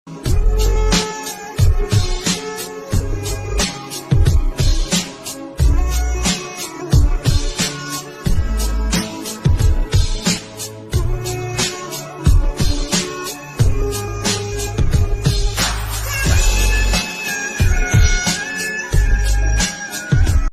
Dark, powerful